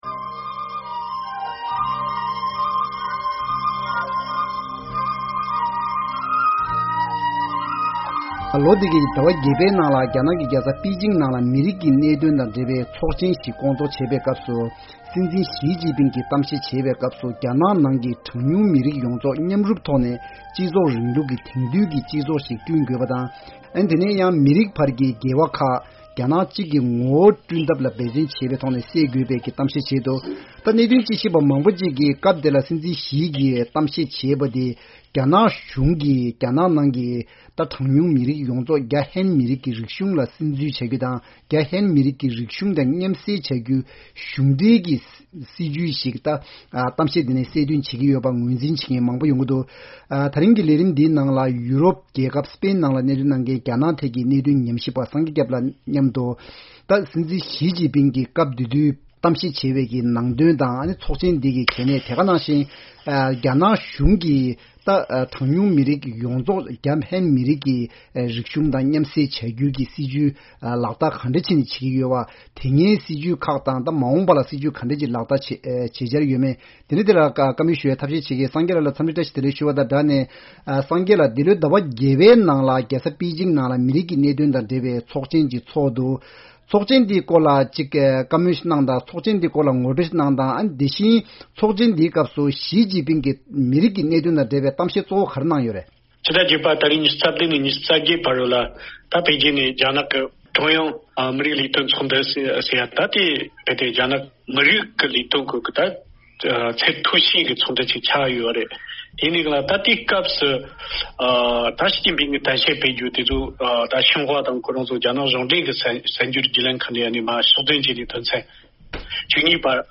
༄༅།། ཉེ་ཆར་རྒྱ་ནག་གི་རྒྱལ་ས་པི་ཅིང་དུ་མི་རིགས་ཀྱི་གནད་དོན་དང་འབྲེལ་བའི་ཚོགས་ཆེན་ཞིག་གི་ཐོག་སྲིད་འཛིན་ཞི་ཅིང་ཕིན་གྱིས་མི་རིགས་དབར་གྱི་འགལ་ཟླ་མེད་པར་འགྱུར་ཐབས་ལ་རྒྱ་ནག་རྒྱལ་ཁབ་གཅིག་སྡུད་འདུ་ཤེས་ཟེར་བའི་གཏམ་བཤད་བྱས་ཡོད་པའི་སྐོར་ལ་གླེང་མོལ་ཞུས་ཡོད།